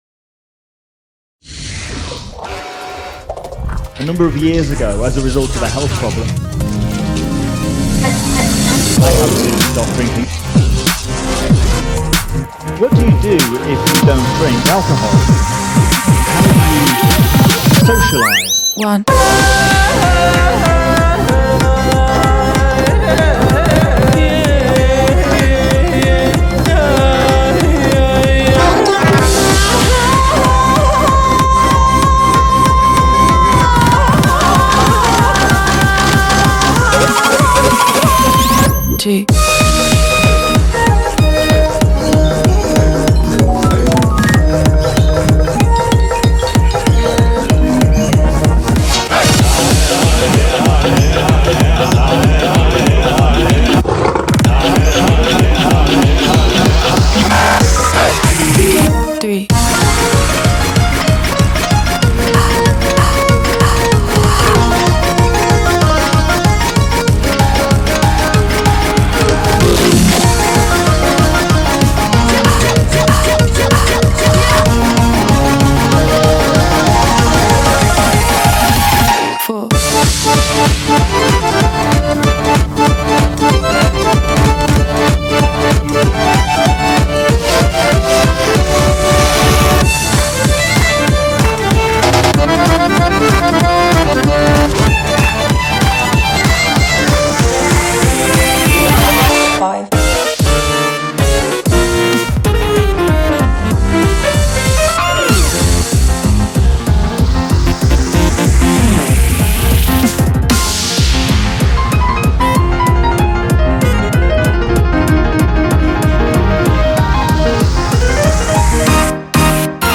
BPM190
WORLDCORE